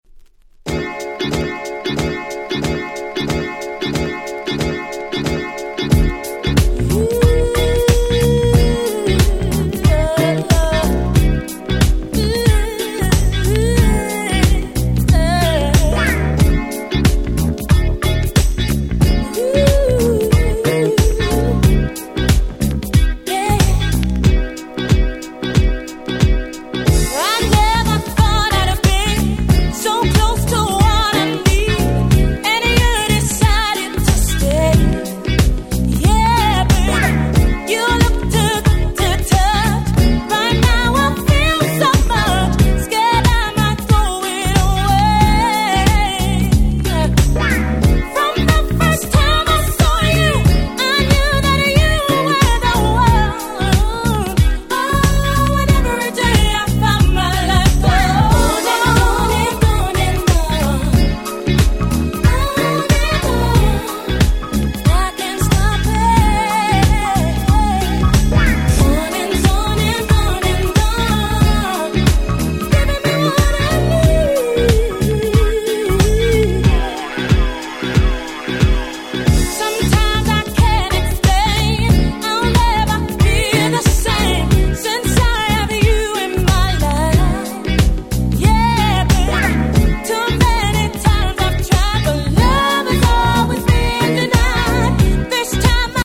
96' Nice UK R&B !!
切ないMelodyはUSのR&Bを思わせますが、その洗練された美しさはまさにUK R&B !!